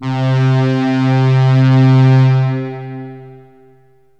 SYNTH LEADS-1 0015.wav